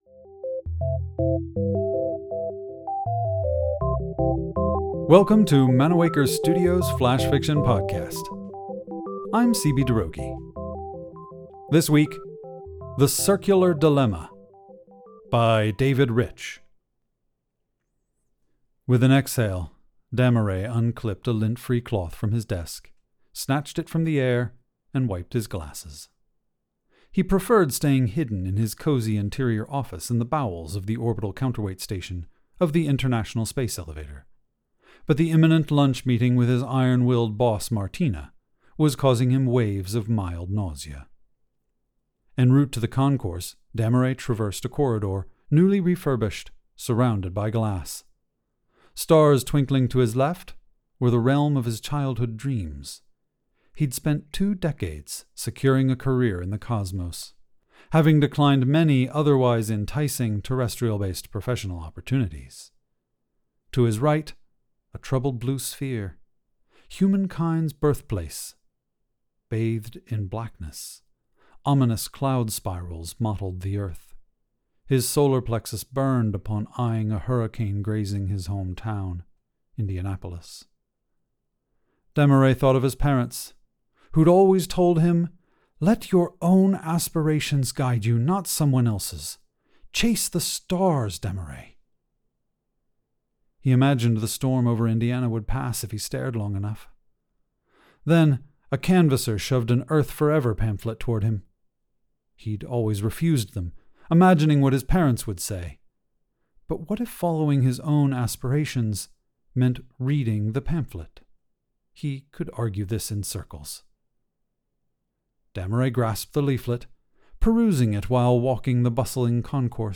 The Flash Fiction Podcast Theme Song is by Kevin McCleod